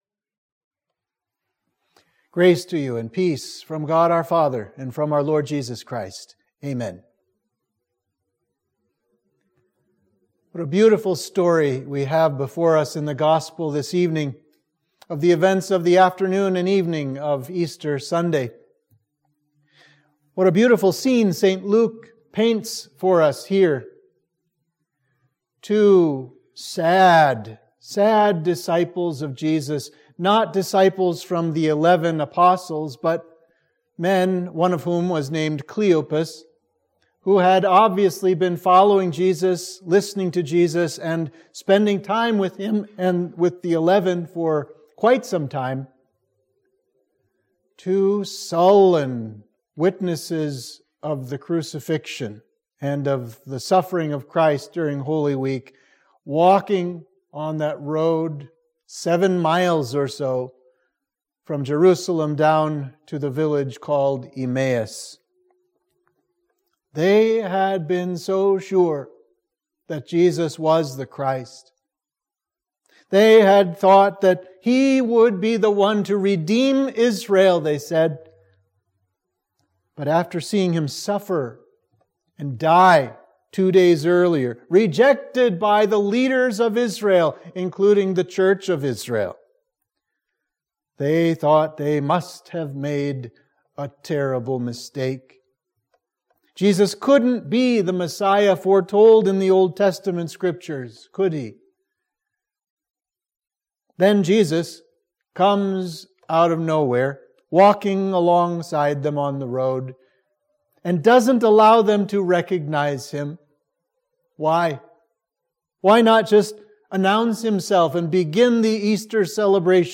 Sermon for the Week of Easter